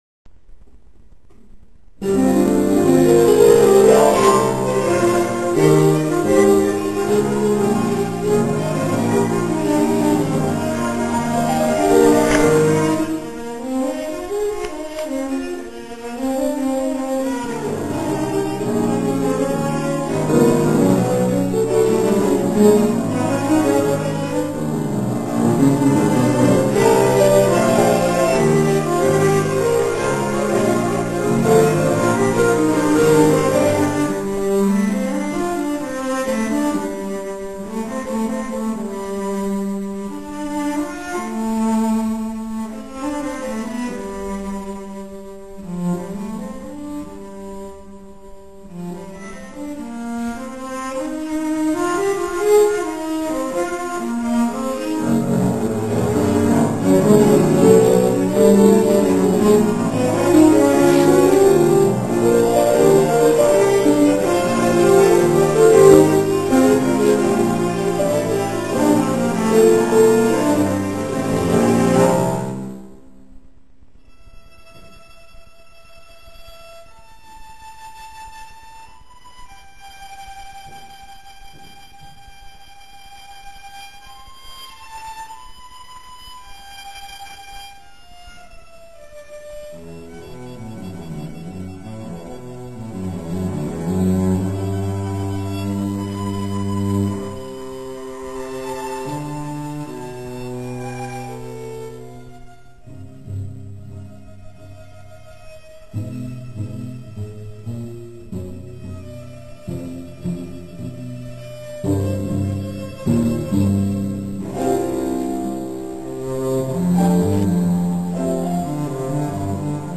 19. komorní koncert na radnici v Modřicích
klavírní trio
A. Aslamas - část klav. tria (2:52-698 kB WMA*)
- ukázkové amatérské nahrávky, v ročence CD Modřice 2006 doplněno: